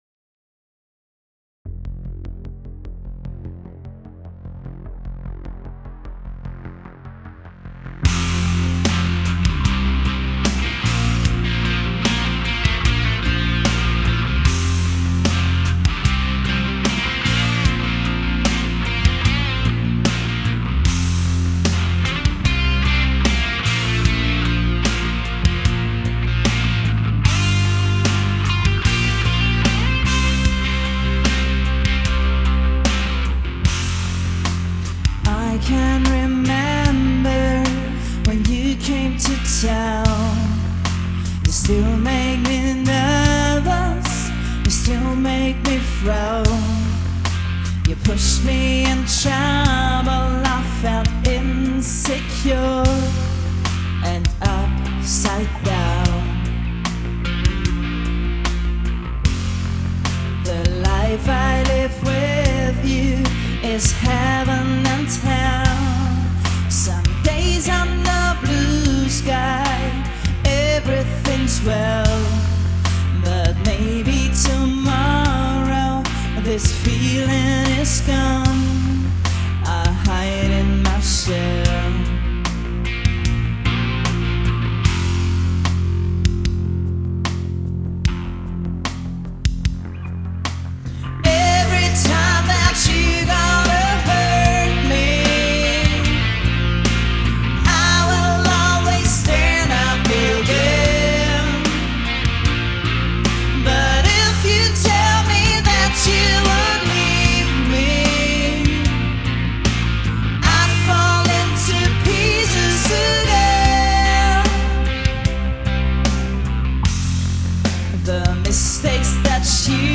Ich habe mich mit Home-Recording und eigenen Songs beschäftigt.
Buddies: Diana & The Diamonds
Die Aufnahme ist das Resultat unserer gemeinsamen Online-Ausarbeitung zu Lockdown-Zeiten und somit quasi die Demo-Variante des Songs.